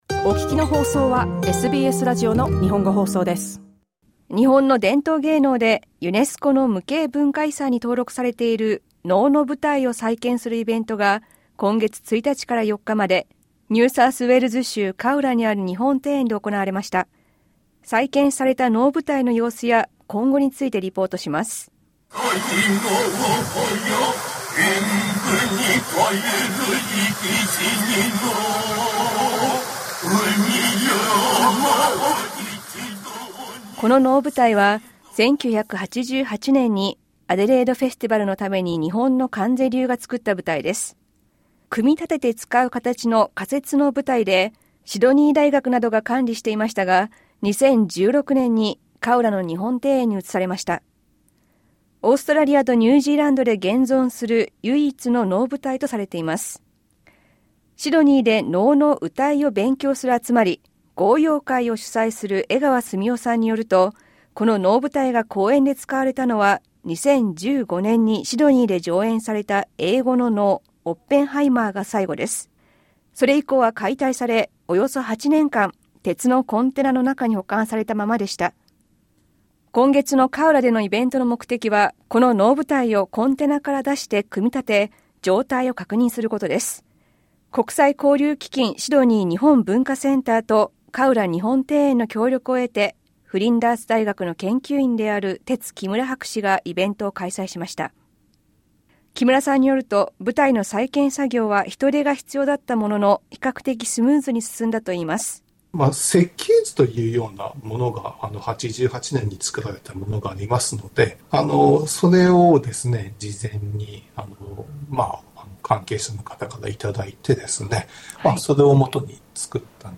カウラ日本庭園での能舞台再建イベント、キーパーソン２人に聞く